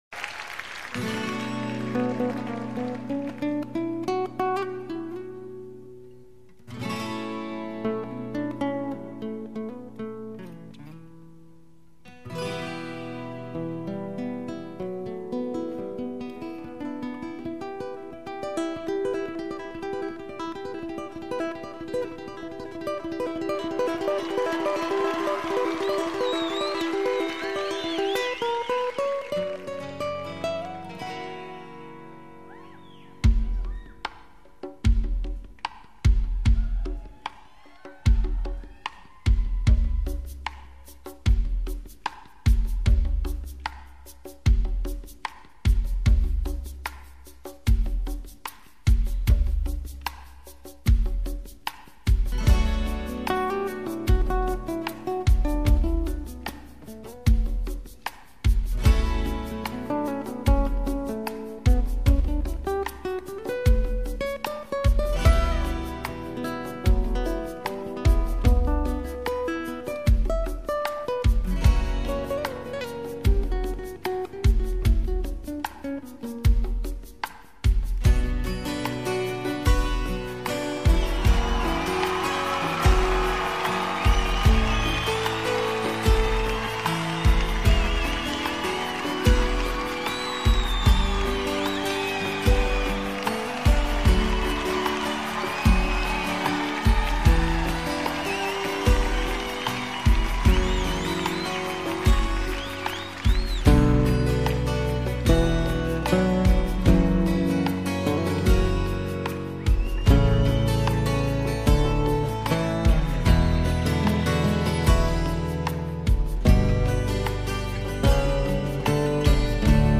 One of the most beautiful acoustic versions.
Live at the Capitol Center